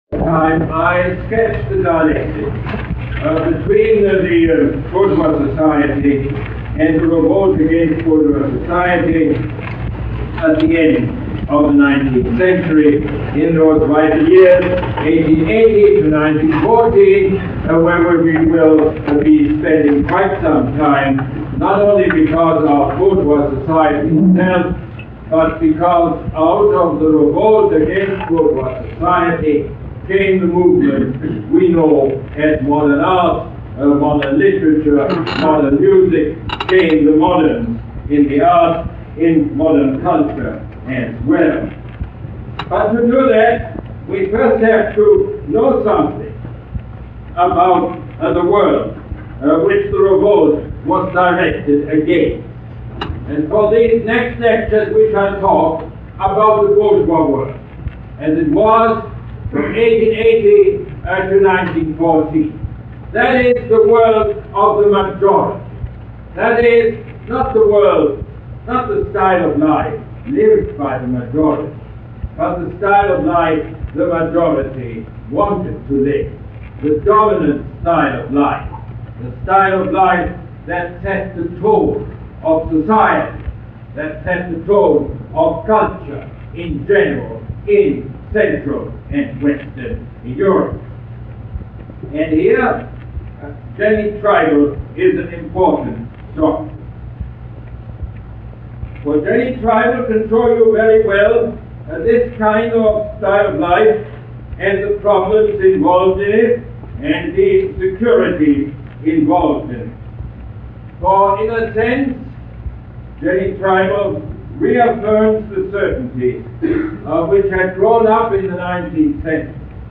Lecture #2 - September 10, 1979